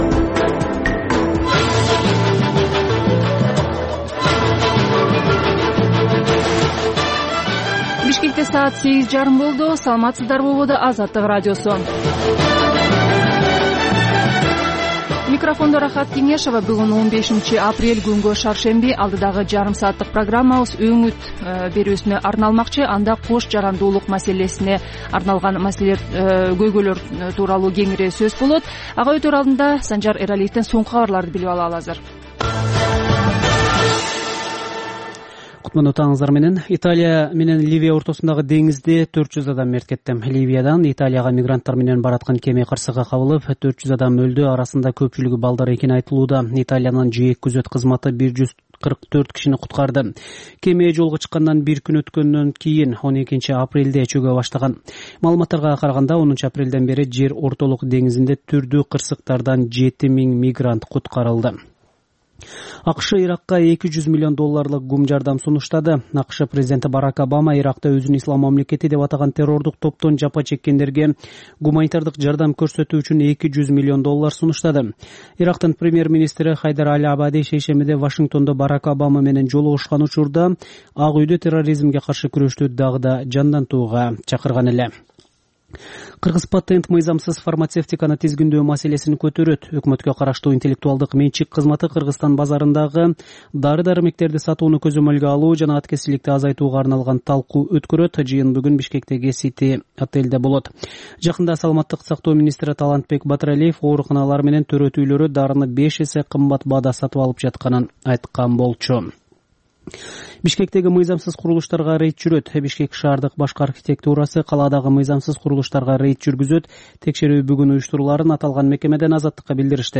Бул таңкы үналгы берүүнүн 30 мүнөттүк кайталоосу жергиликтүү жана эл аралык кабарлар, ар кыл орчун окуялар тууралуу репортаж, маек, күндөлүк басма сөзгө баяндама, «Коом жана турмуш» түрмөгүнүн алкагындагы тегерек үстөл баарлашуусу, талкуу, аналитикалык баян, сереп жана башка берүүлөрдөн турат.